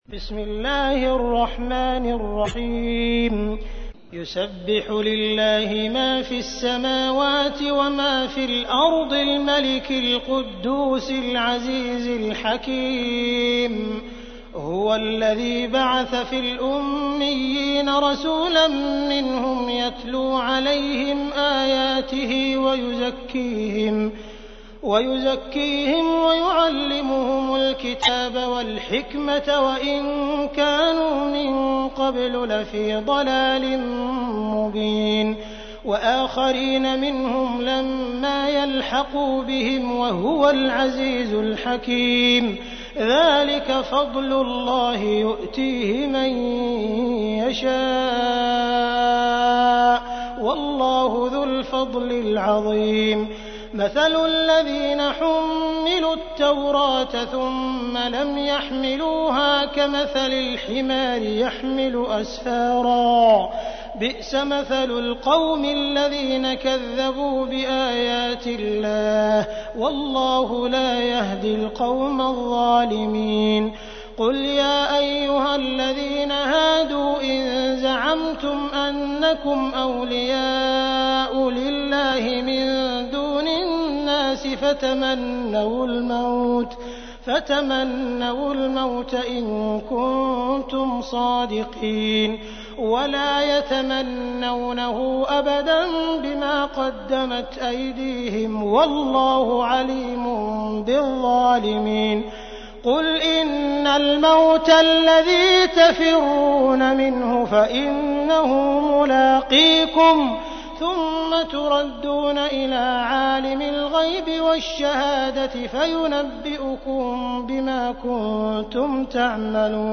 تحميل : 62. سورة الجمعة / القارئ عبد الرحمن السديس / القرآن الكريم / موقع يا حسين